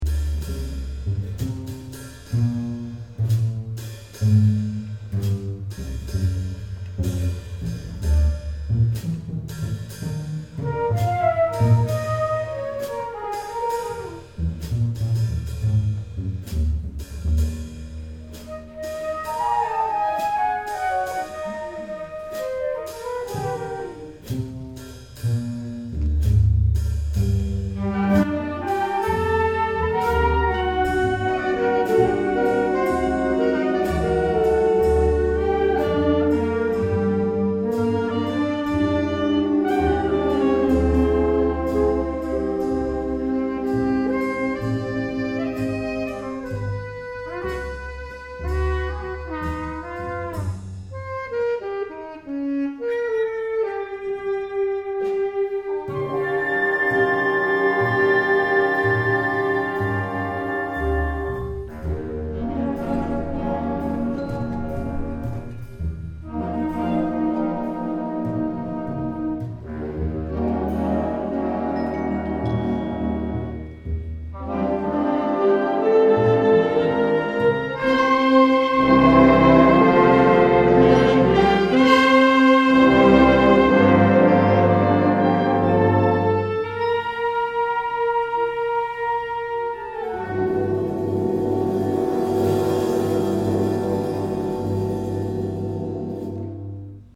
2008 Summer Concert
June 22, 2008 - San Marcos High School